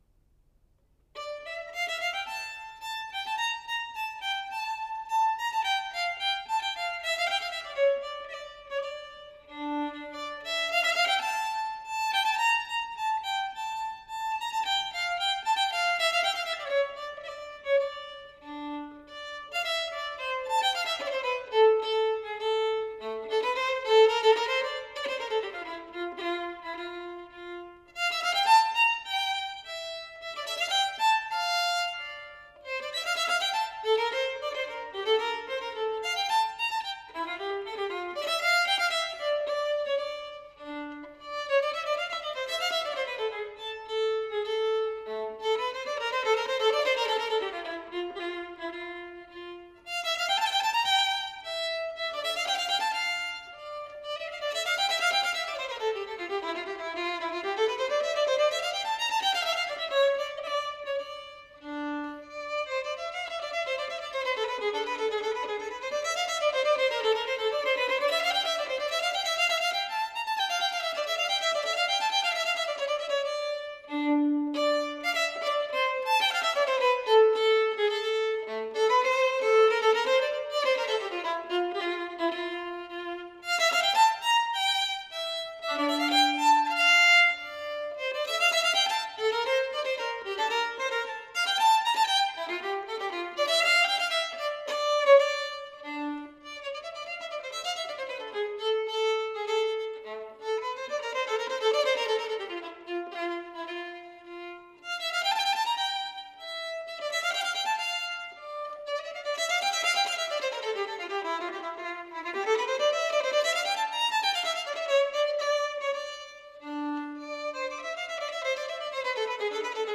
Violin alone: